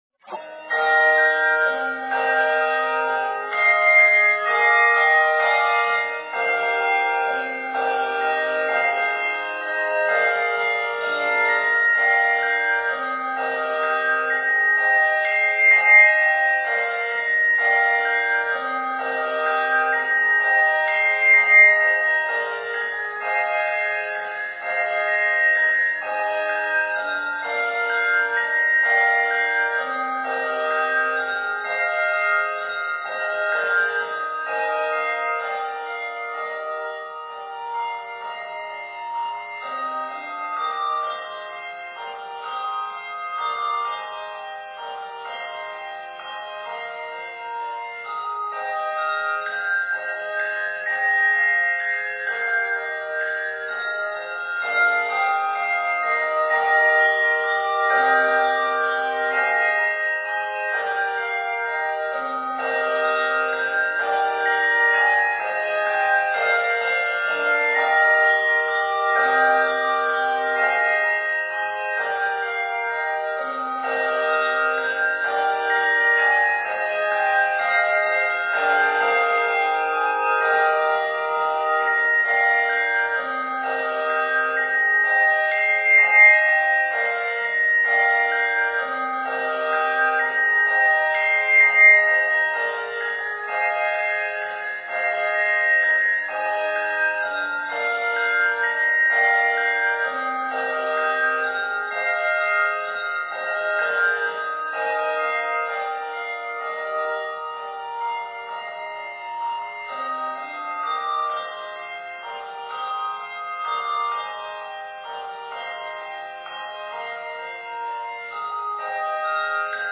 Octaves: 3